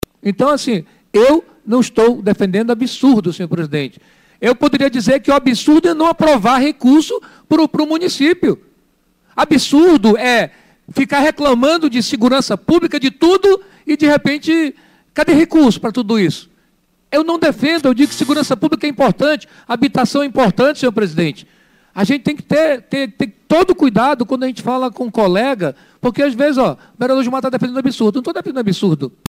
Quem saiu em defesa da aprovação do PL foi o vereador Gilmar Nascimento (Avante).